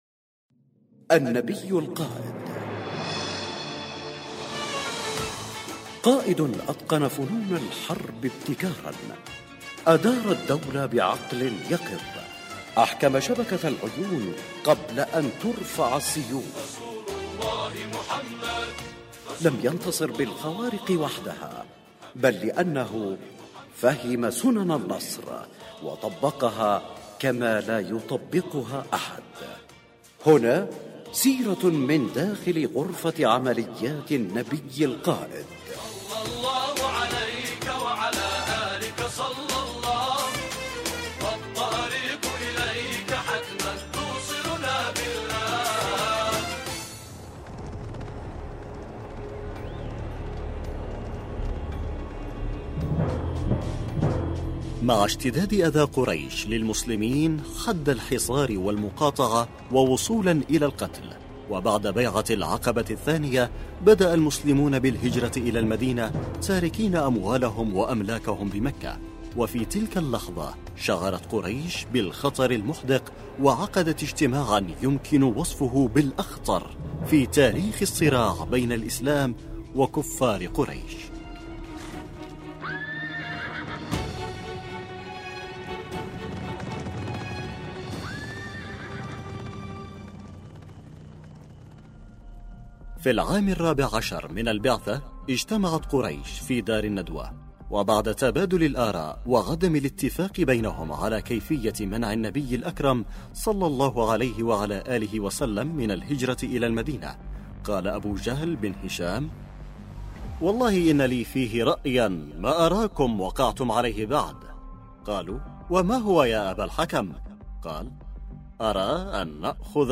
الحلقة (2) النبي القائد، برنامج إذاعي يقدم الجوانب العسكرية والأمنية في السيرة النبوية للنبي الاكرم صلى الله عليه واله مع الاعتماد بشكل كلي على ما ذكره السيد القائد يحفظه الله في محاضراته خلال رمضان وخلال المولد النبوي الشريف.